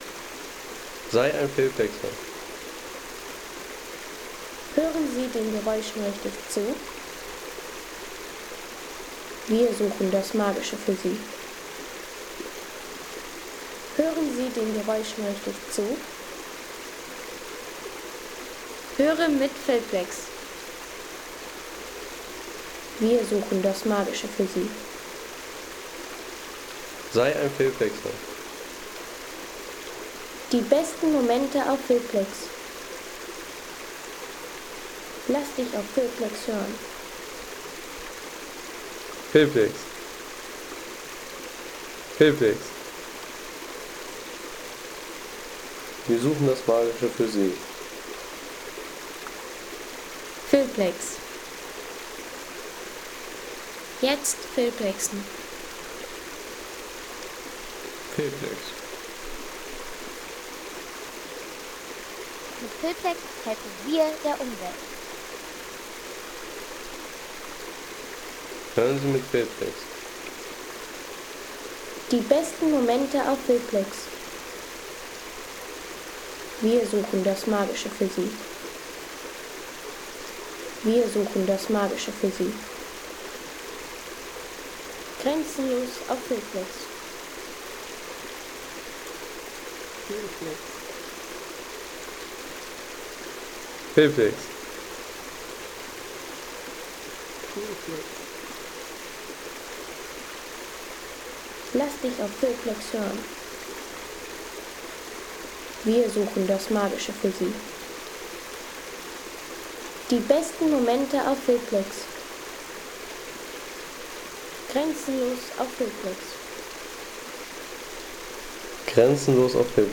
Steinhöfer Wasserfall Sound in Kassel | Feelplex
Atmosphärischer Wasserfall-Sound vom Steinhöfer Wasserfall in Kassel. Ideal für Film, Postkarten und natürliche Hintergrundszenen mit ruhigem Wasserrauschen.
Der Steinhöfer Wasserfall in Kassel mit beruhigendem Wasserrauschen, Felsstruktur und stiller Parkatmosphäre für Film und Hintergrundszenen.